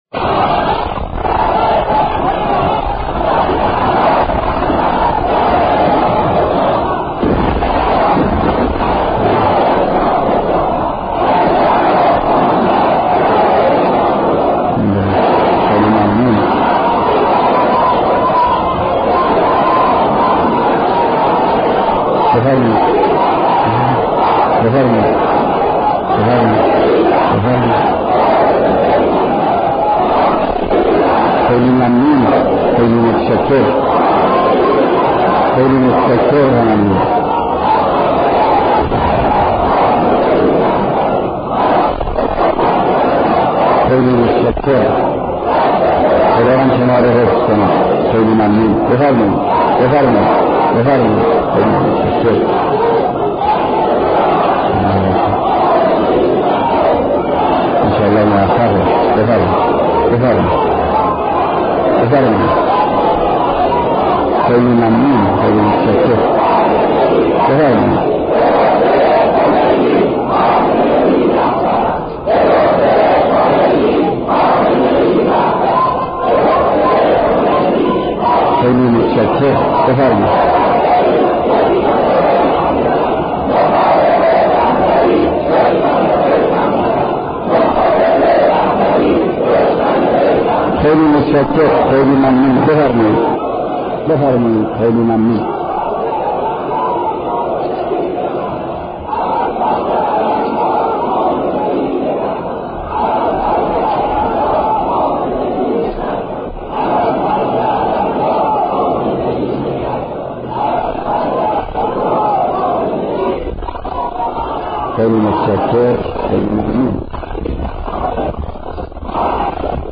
صوت کامل بیانات
سخنرانی